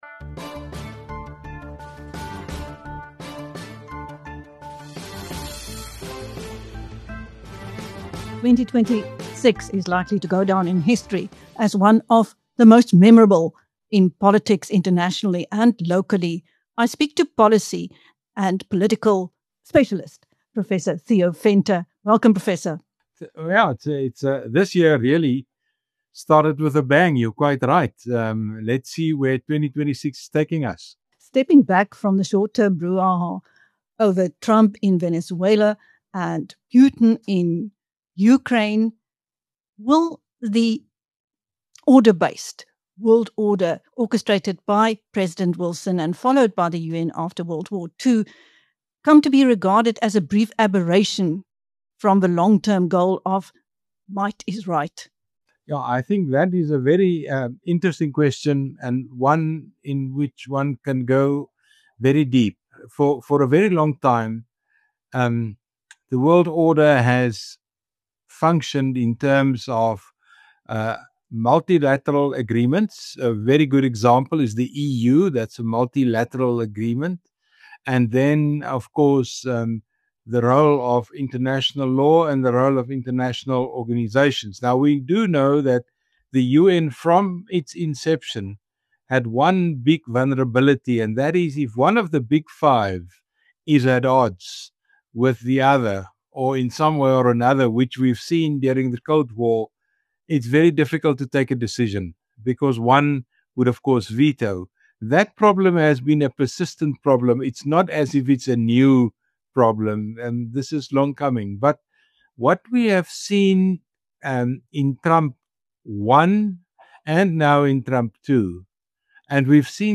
In his latest interview